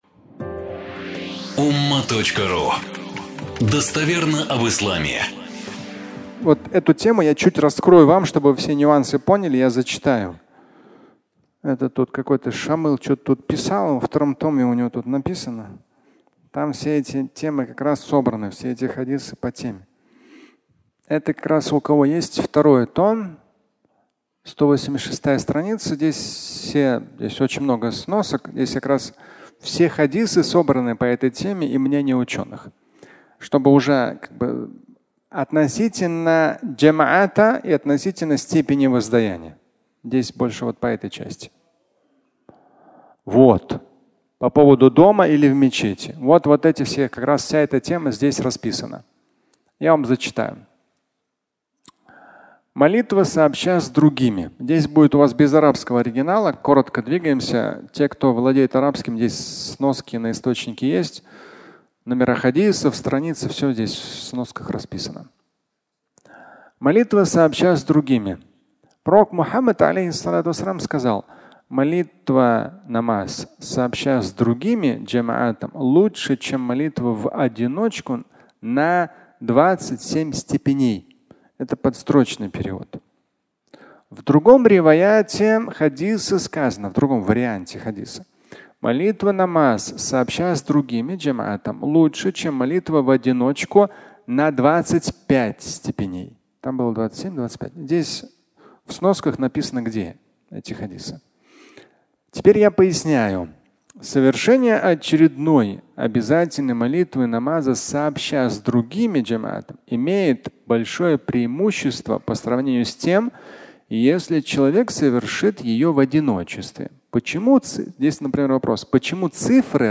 На 27 степеней (аудиолекция)